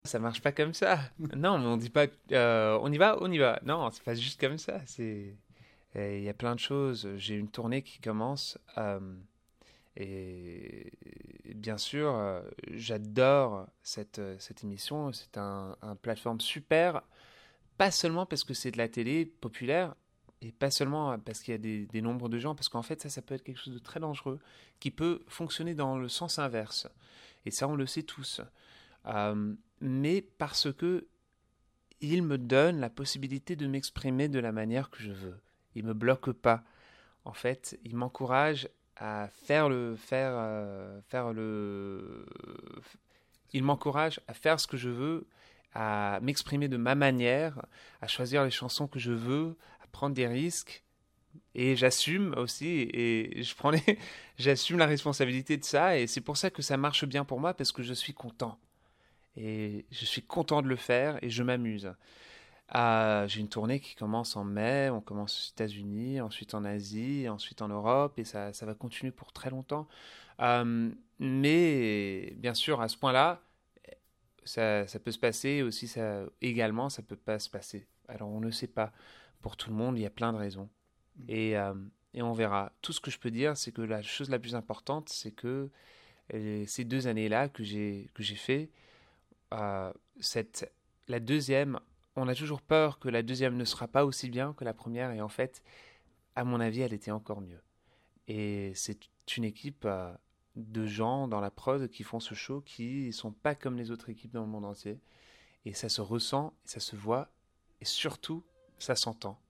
Mika en interview pour "Tendance Ouest"